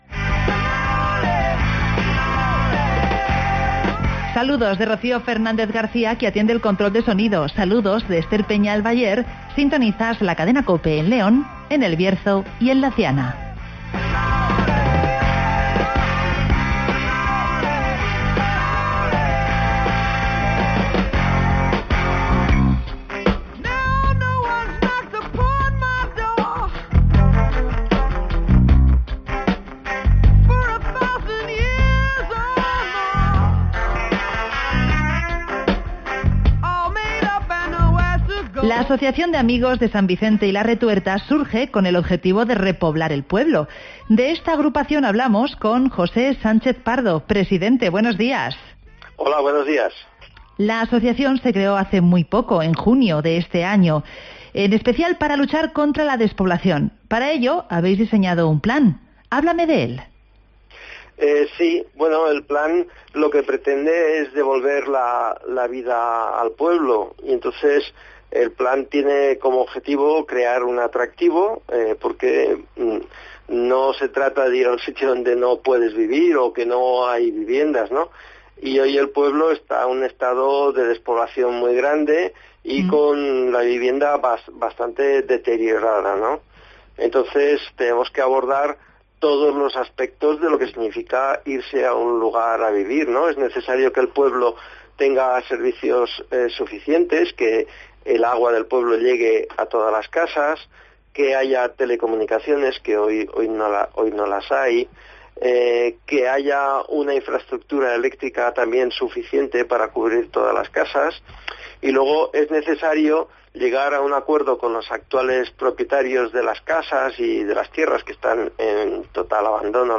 San Vicente, pueblo del municipio de Arganza lucha contra la despoblación (Entrevista